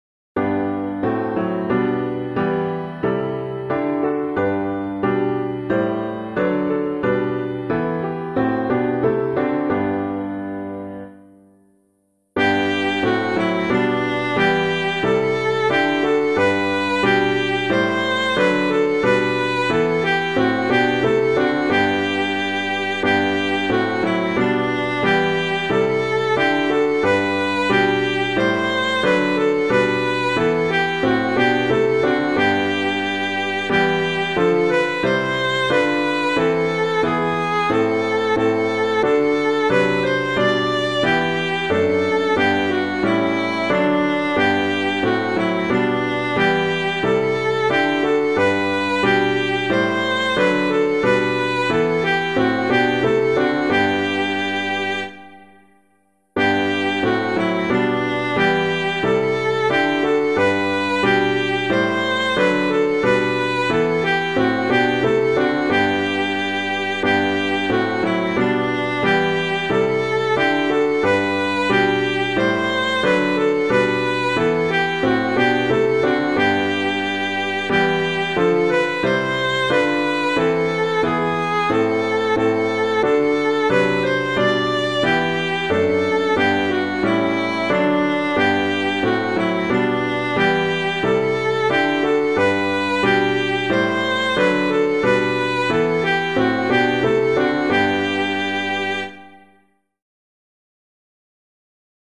piano
Sing We Now the Glorious Martyrs [Glen - IN BABILONE] - piano.mp3